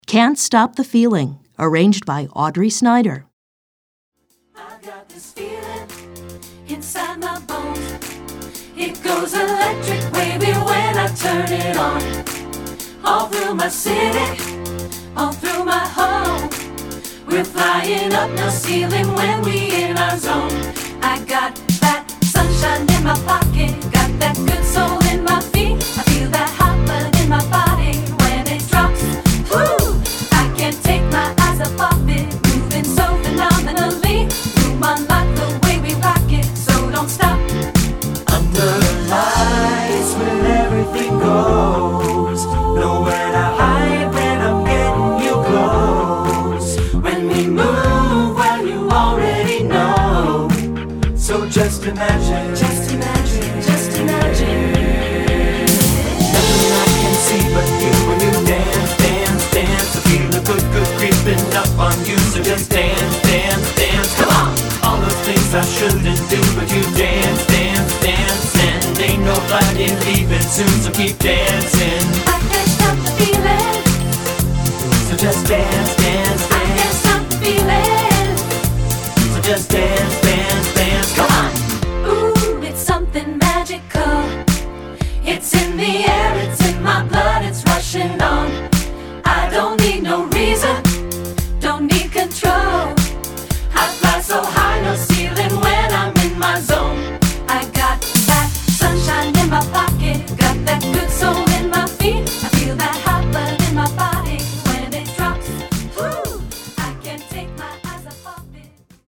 This Funk tune